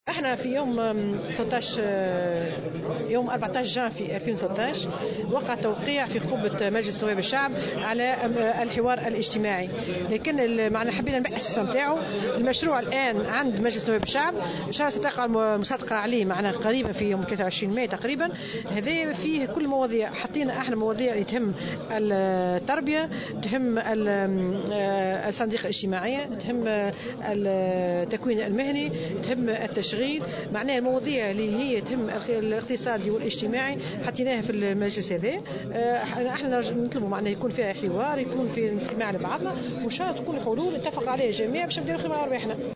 قالت رئيسة اتحاد الصناعة والتجارة والصناعات التقليدية وداد بوشماوي في تصريح لمراسلة الجوهرة اف ام على هامش المشاركة في احتفالات عيد الشغل، إن مشروع المجلس الوطني للحوار الاجتماعي في قبة البرلمان ستقع المصادقة عليه في 23 ماي 2017.